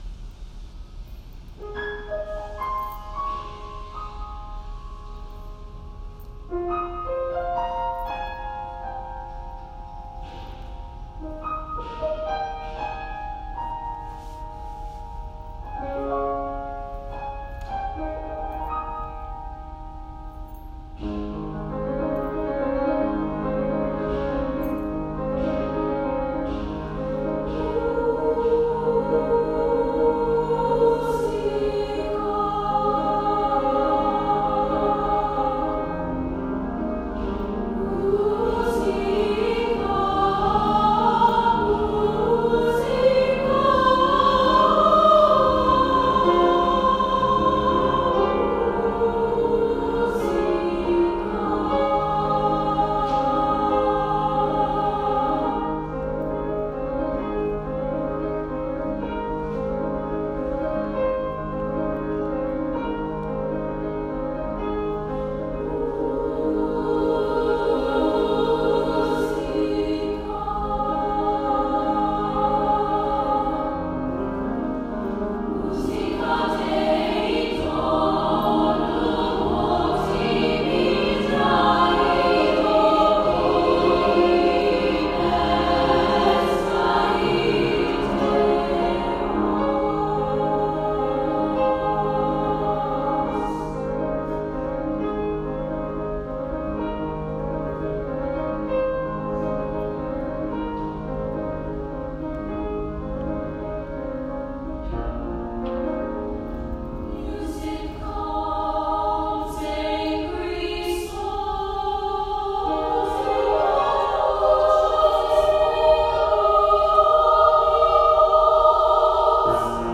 SSAA Chorus & Piano